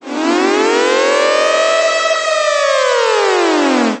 lwsiren-raid.ogg